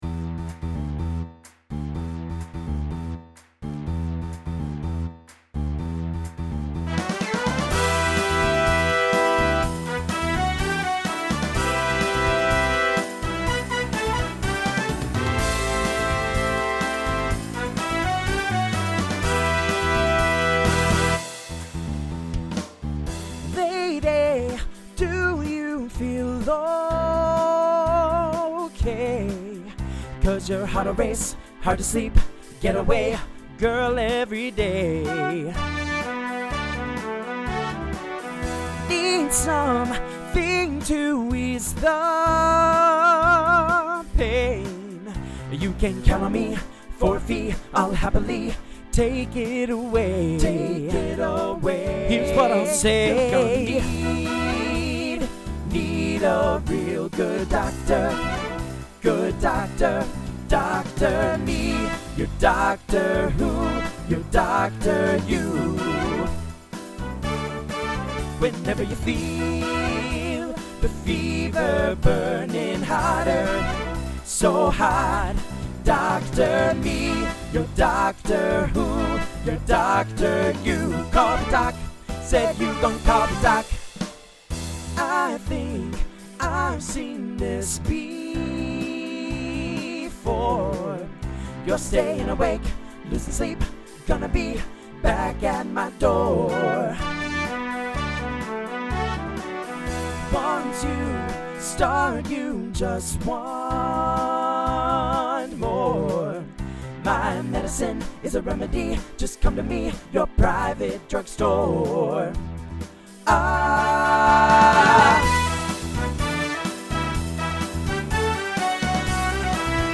Voicing TTB Instrumental combo Genre Rock